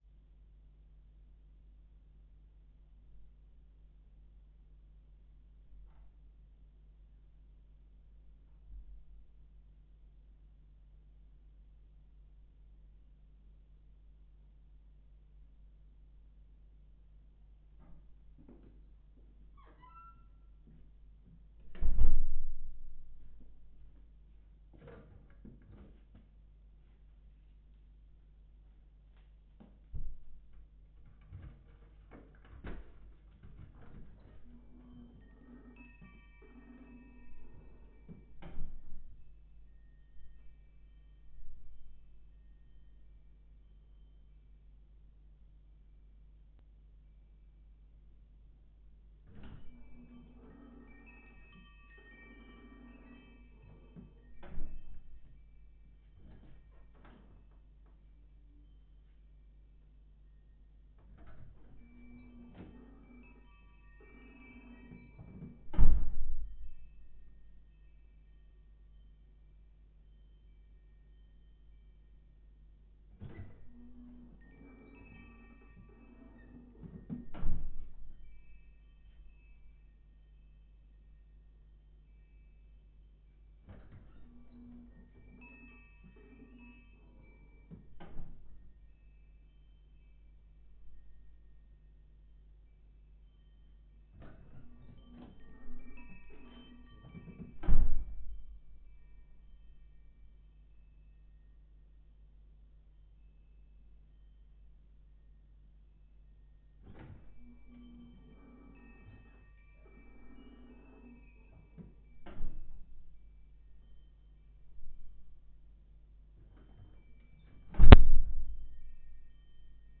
door1.wav